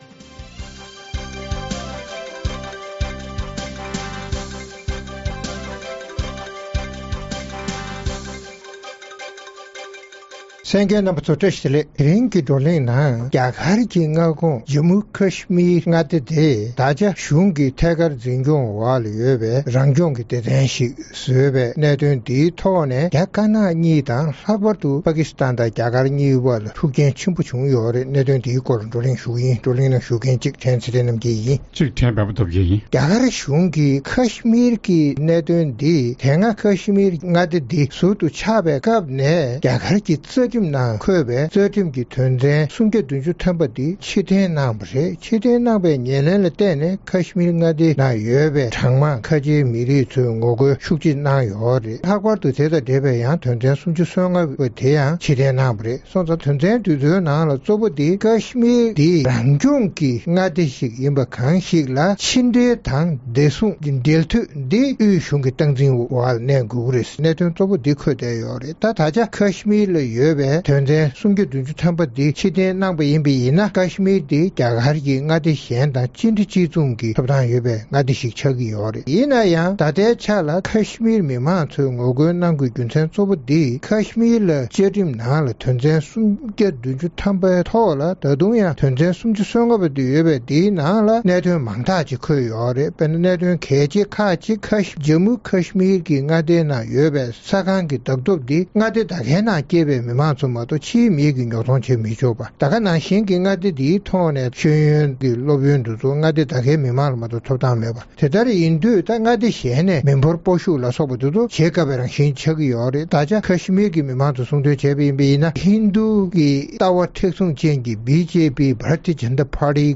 རྩོམ་སྒྲིག་པའི་གླེང་སྟེགས་ཞེས་པའི་ལེ་ཚན་ནང་། རྒྱ་གར་གཞུང་གིས་རྒྱ་གར་དང་ Pakistan གཉིས་དབར་རྩོད་གཞི་ཡོད་པའི་ Kashmir མངའ་སྡེའི་ནང་དེ་སྔའི་རང་སྐྱོང་གི་གནས་བབ་ཕྱིར་བསྡུ་གནང་བའི་ཉེར་ལེན་གྱིས་ཡུལ་གྲུ་གཉིས་དབར་འབྲེལ་ལམ་ཇེ་སྡུག་ཏུ་འགྲོ་བཞིན་པ་དང་། རྒྱ་དཀར་ནག་གཉིས་ཀྱི་འབྲེལ་བར་ཤུགས་རྐྱེན་ཇི་ཐེབས་སོགས་ཀྱི་སྐོར་རྩོམ་སྒྲིག་འགན་འཛིན་རྣམ་པས་བགྲོ་གླེང་གནང་བ་གསན་རོགས་གནང་།།